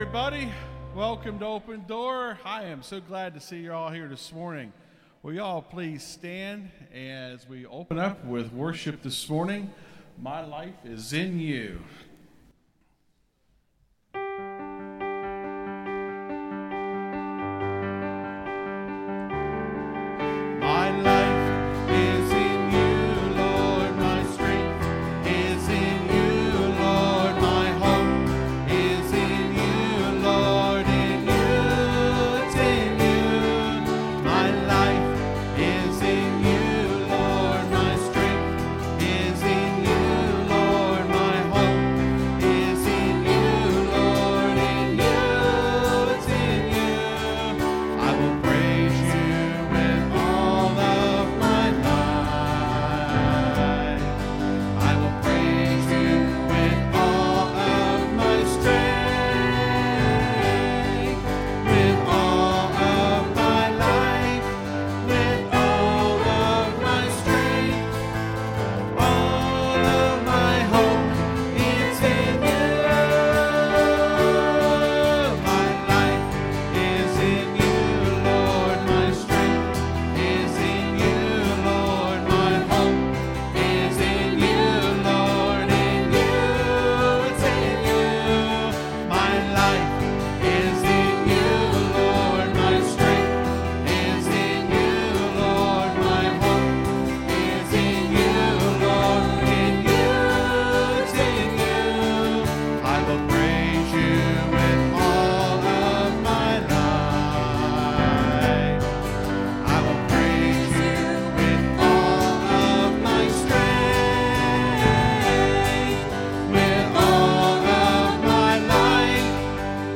(Sermon starts at 28:00 in the recording).